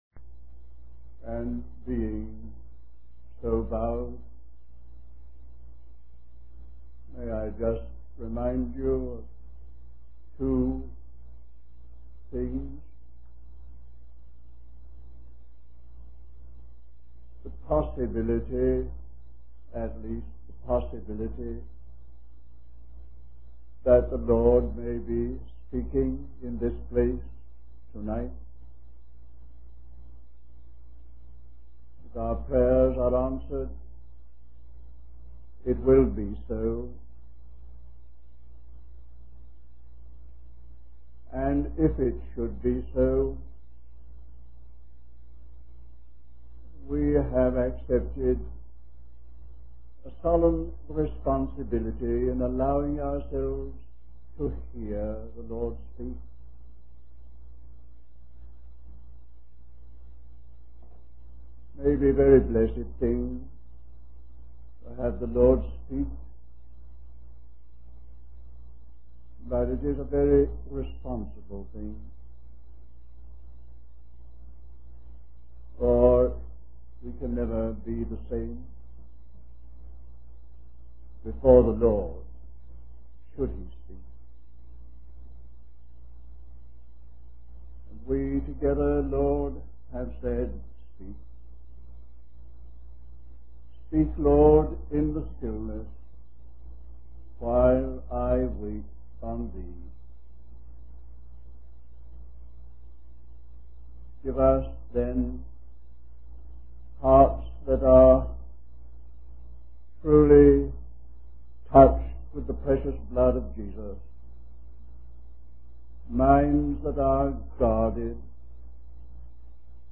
Wabanna (Atlantic States Christian Convocation)